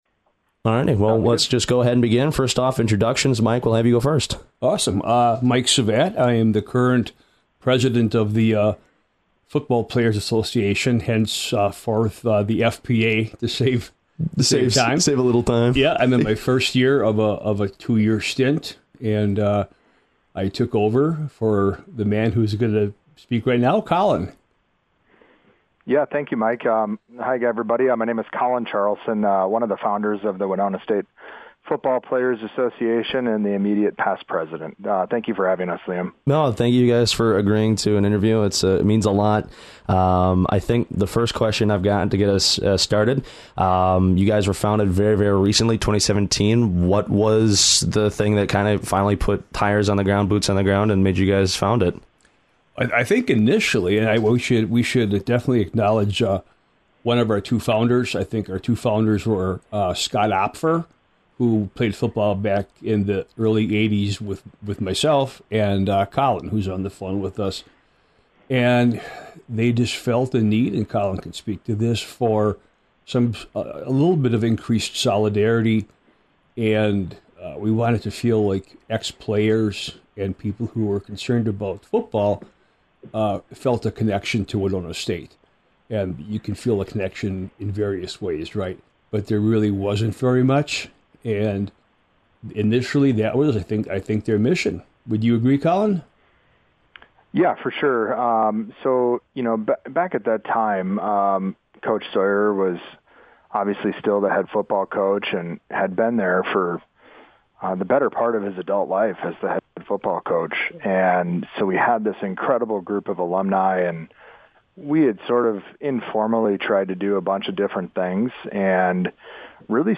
fpa-interview.mp3